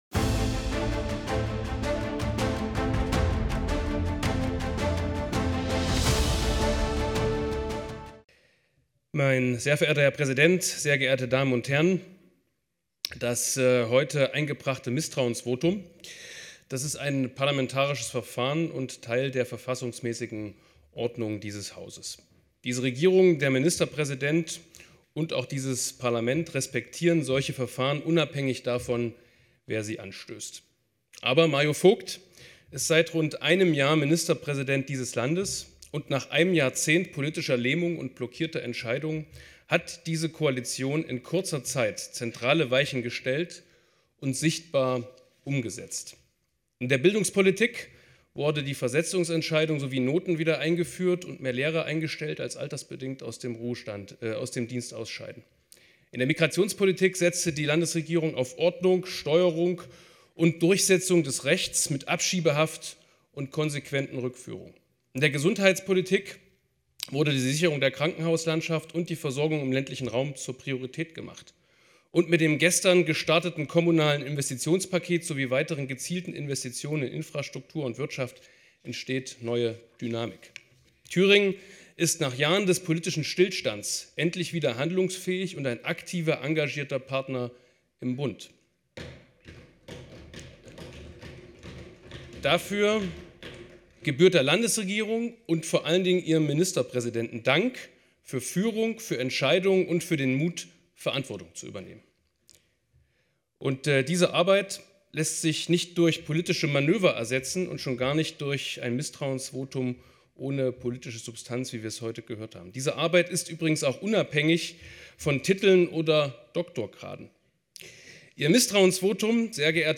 Über mehrere Wochen wurde über das Verhalten des Thüringer Innenministeriums bei internen Ermittlungen der Polizei gegen Polizisten in Saalfeld sowie Mitglieder der Gewerkschaft der Polizei (GdP) landespolitisch heftig diskutiert. Die Debatte gipfelte in einem von der AfD-Fraktion beantragten Sonderplenum des Thüringer Landtages am 16. Januar 2026. Wir dokumentieren an dieser Stelle die Rede von Ronald Hande (Die Linke) über dieses Thema im Thüringer Parlament.